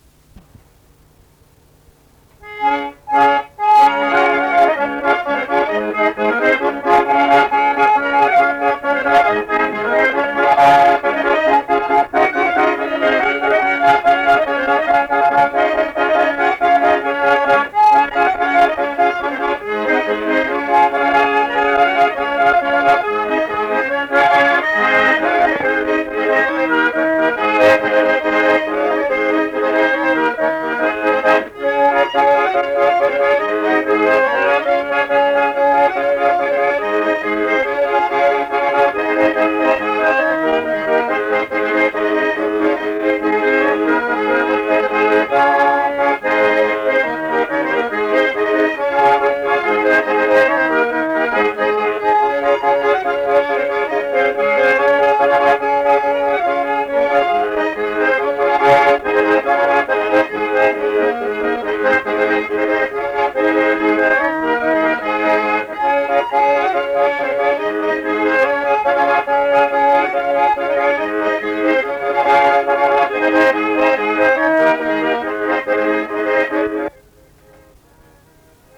Krakių polka
Dalykas, tema šokis
Erdvinė aprėptis Krakės
Atlikimo pubūdis instrumentinis
Instrumentas smuikas mandolina bosas